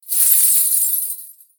Danza árabe, bailarina da un golpe de cadera con un pañuelo de monedas 03
Sonidos: Acciones humanas